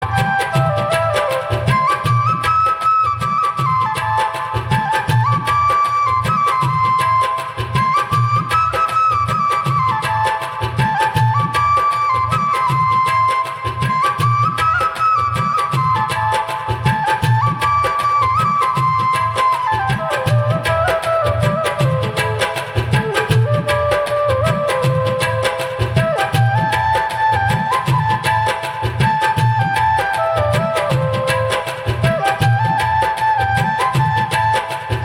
Instrumental Ringtone.